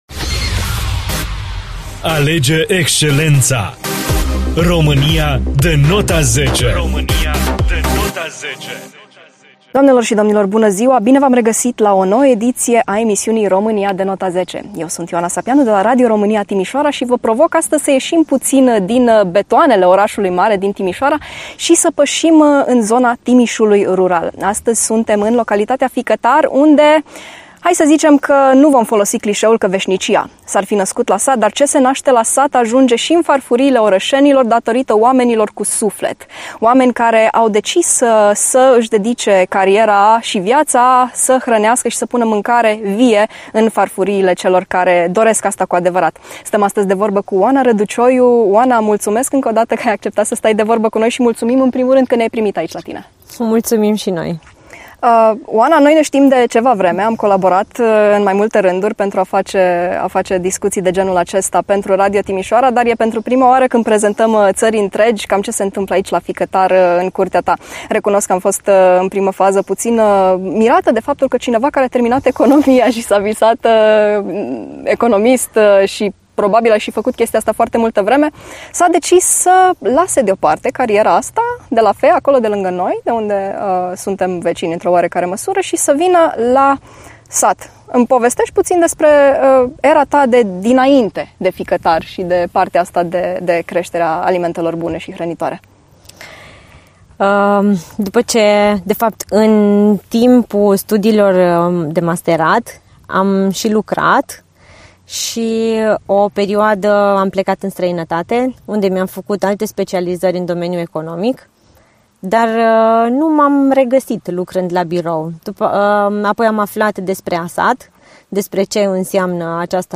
In spatele casei lor e un mic colț de rai, în care a poposit și echipa Radio Timișoara, pentru o nouă poveste România de nota zece.